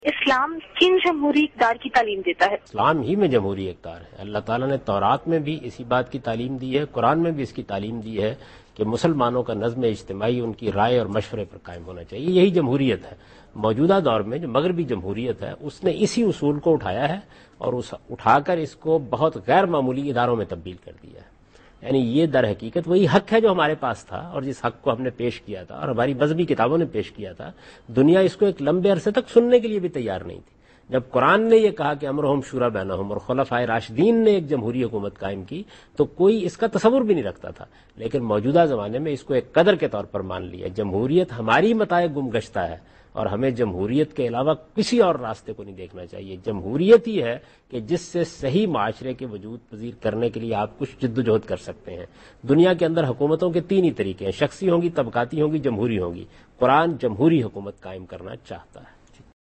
TV Programs
Ghamidi answers to an important question on what Islam has to say on establishment of Democratic system of Government.
جاوید احمد غامدی اسلام میں جمہوریت کی اقدار کے متعلق سوالات کا جواب دے رہے ہیں